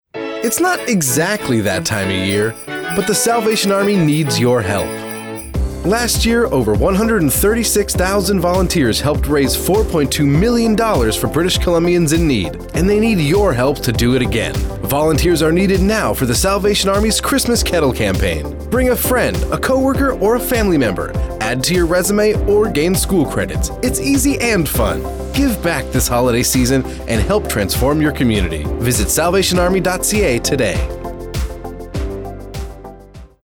Kettle Radio Ad Salvation Army Kettle_Volunteers